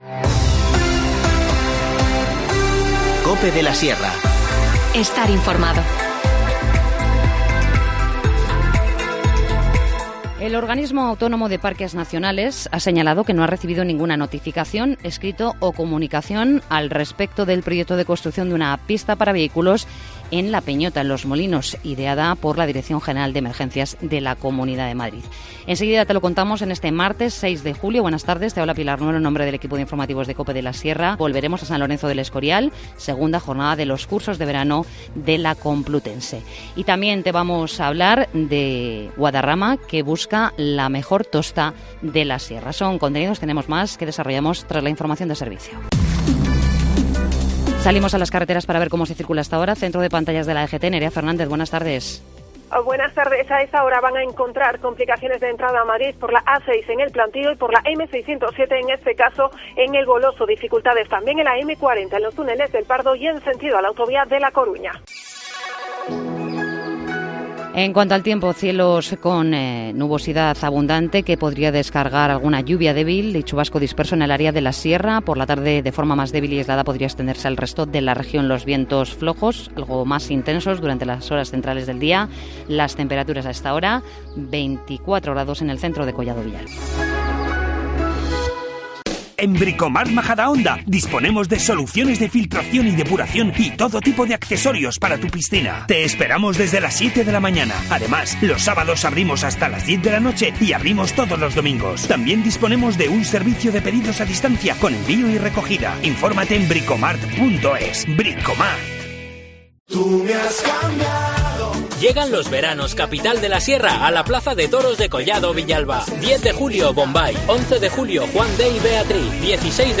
Informativo Mediodía 6 julio